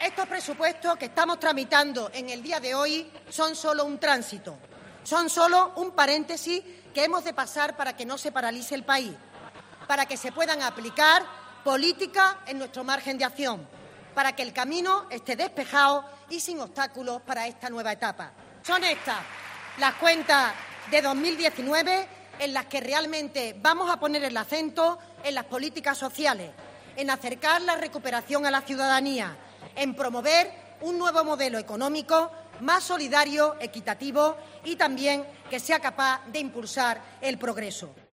La ministra de Hacienda, María Jesús Montero, ha señalado en el Pleno del Senado que el proyecto de ley de Presupuestos de 2018 "son de pasado y no de futuro" y ha recalcado que son de "tránsito" y que el nuevo Gobierno de Pedro Sánchez los asumirá por "responsabilidad".
"No es el que compartimos", ha dicho bajo las protestas continuas del grupo parlamentario del PP, que ha interrumpido el discurso de la ministra en varias ocasiones.